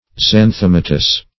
Xanthomatous \Xan*thom"a*tous\, a.